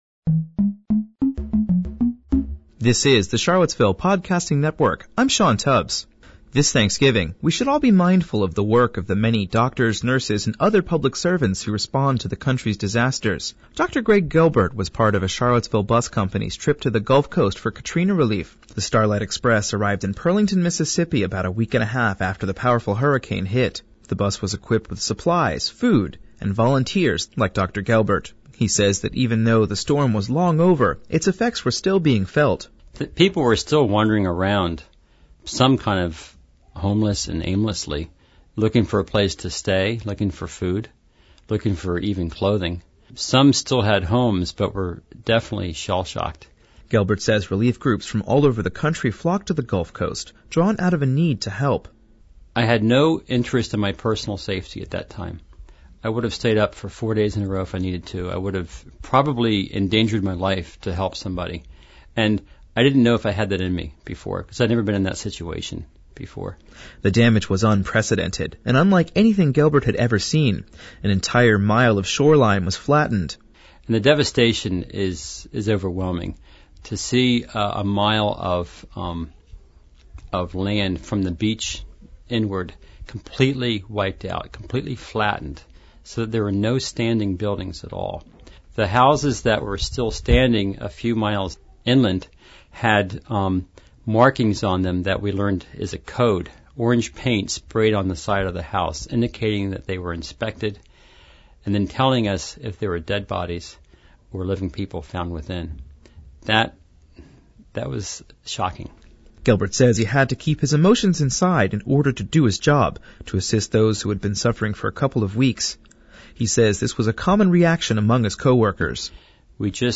I spoke with him yesterday in his practice, and he told me turned to writing to help deal with his emotions upon returning home.